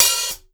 Cardi Open Hat 4.wav